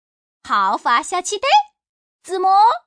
Index of /hunan_feature2/update/1271/res/sfx/changsha_woman/